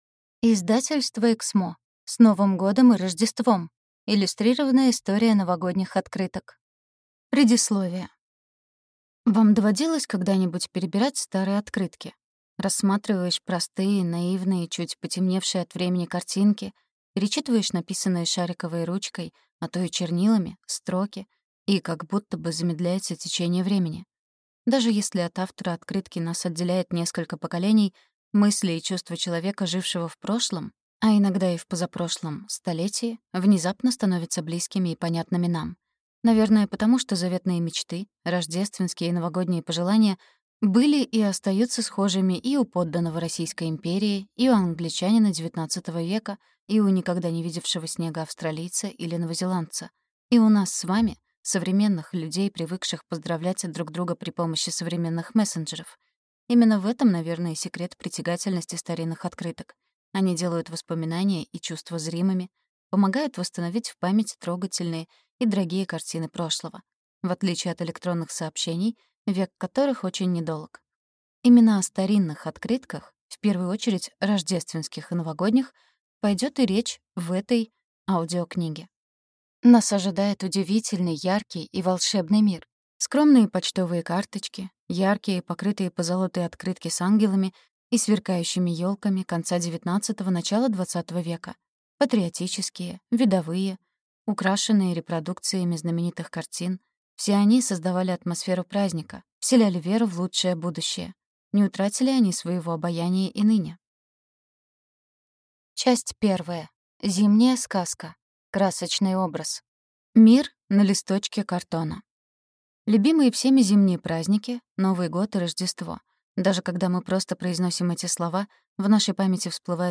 Аудиокнига С Новым годом и Рождеством! Иллюстрированная история новогодних открыток | Библиотека аудиокниг